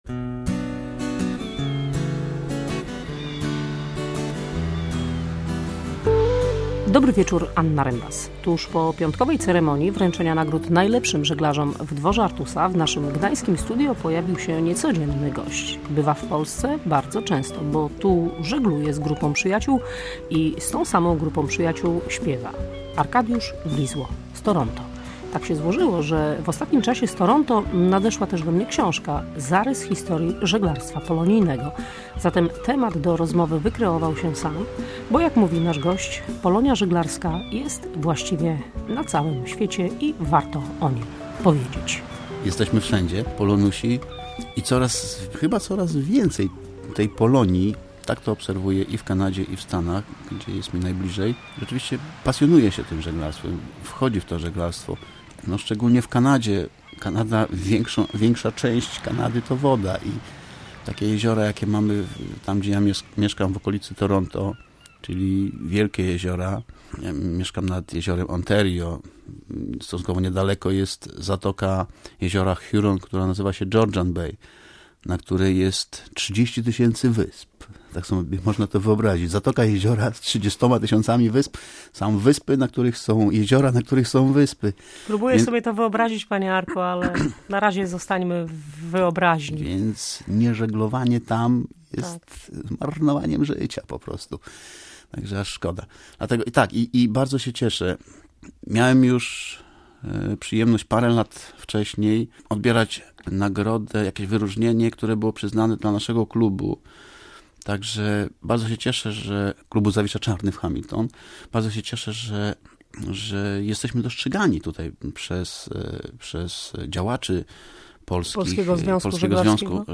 Odwiedził nasze studio i opowiadał o żeglowaniu w Kanadzie i USA. Zagrał też kilka swoich utworów z wydanych w ostatnich latach płyt.